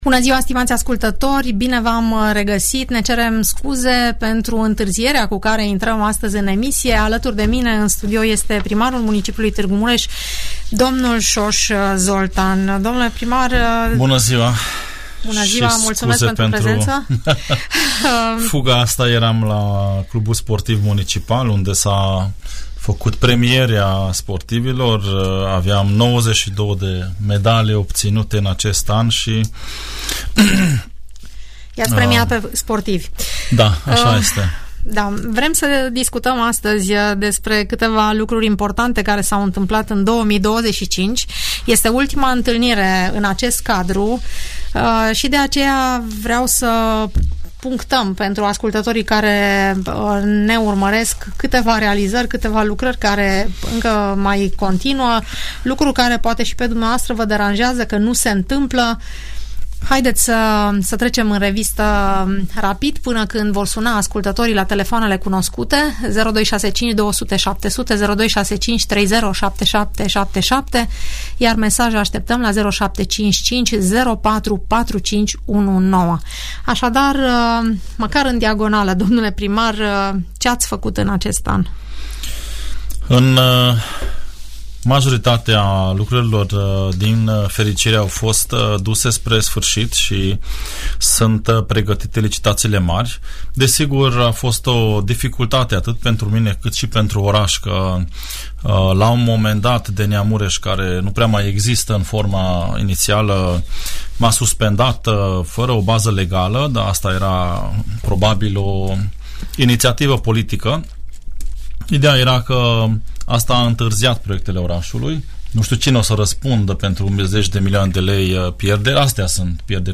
Primarul municipiului Tg. Mureș în direct la Radio Tg Mureș - Radio Romania Targu Mures